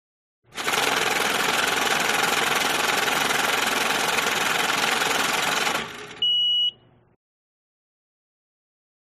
Звук денежного аппарата при пересчете